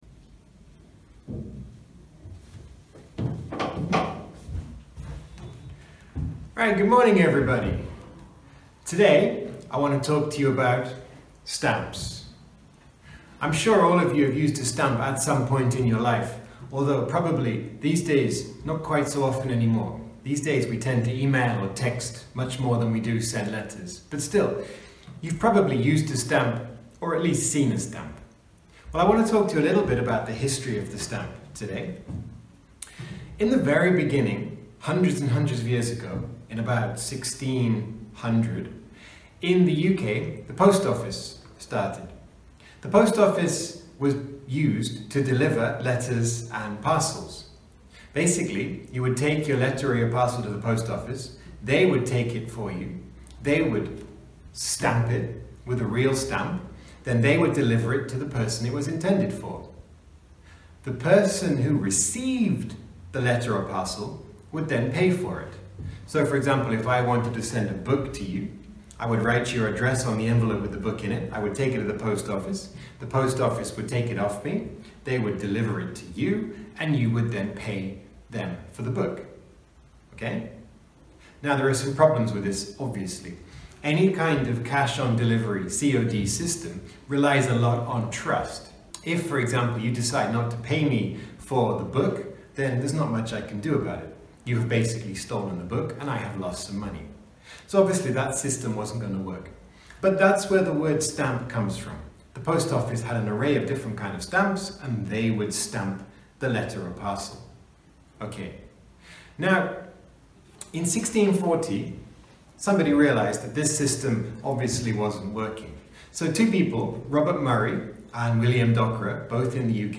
Posted in Long talk | Tagged , , | Comments Off on #5 The Stamp